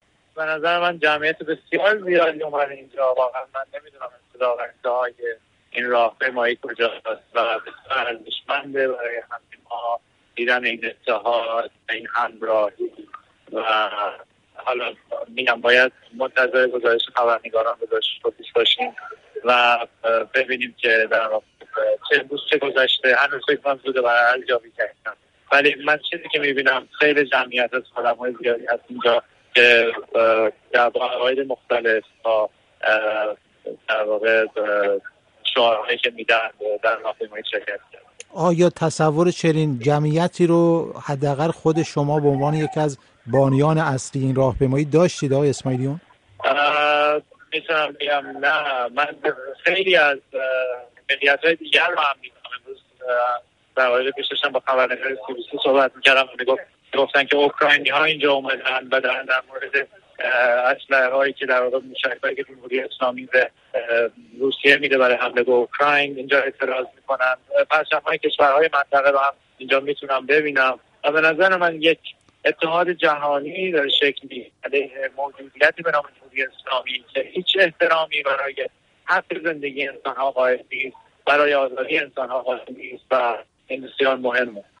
گفت‌وگو با حامد اسماعیلیون در مورد تجمع همبستگی ایرانیان در برلین